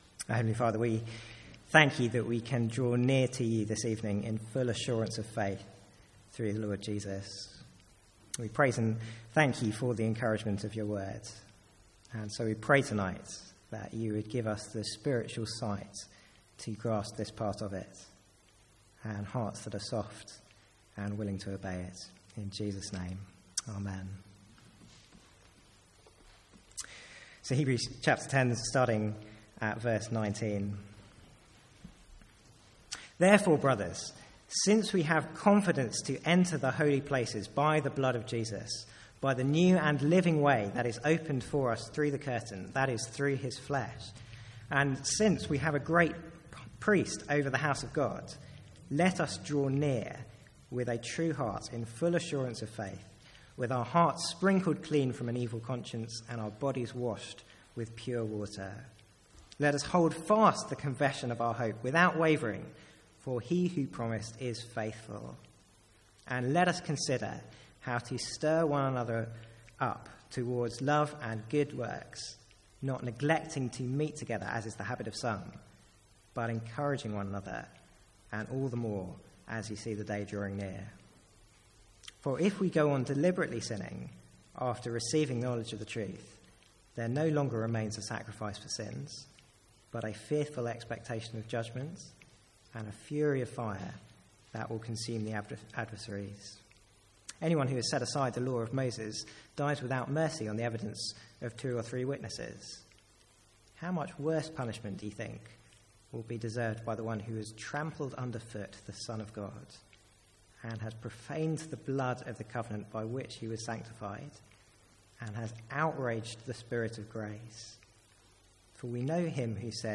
Sermons | St Andrews Free Church
From the Sunday evening series in Hebrews.